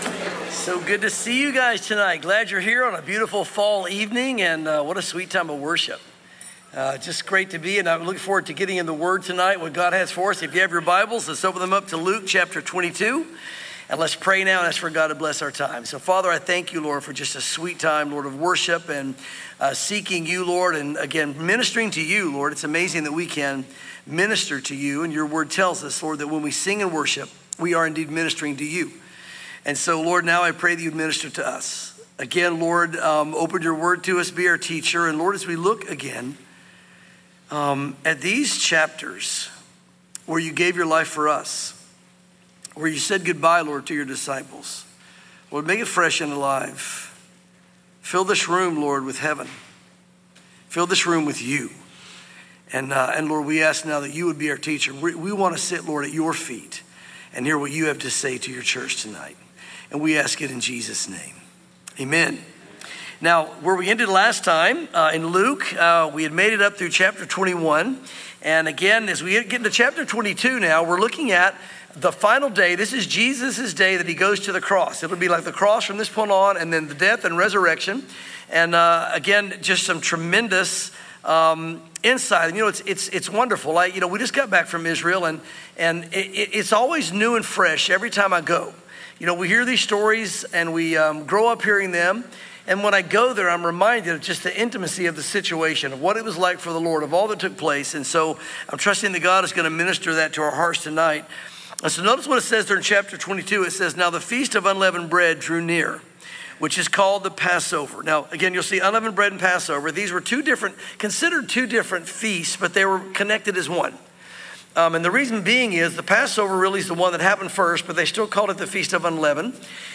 sermons Luke 22:1-62